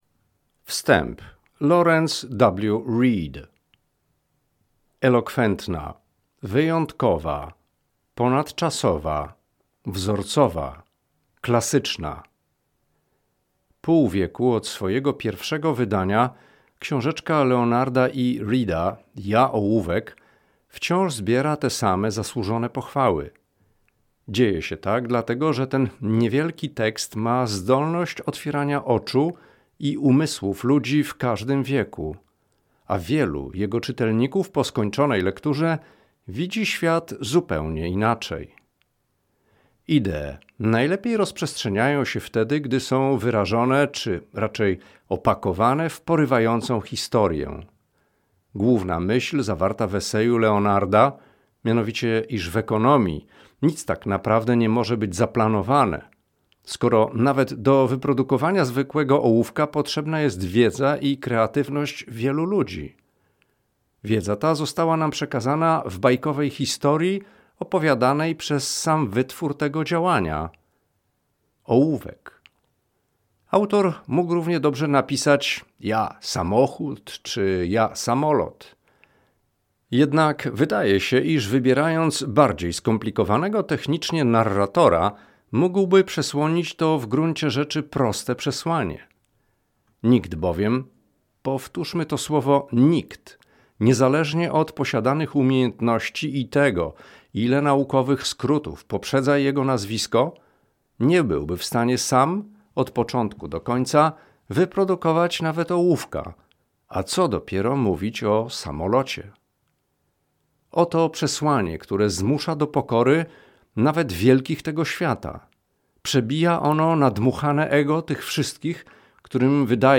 Ja, ołówek. O czym nie wiedzą rządzący tego świata. - Leonard E. Read - audiobook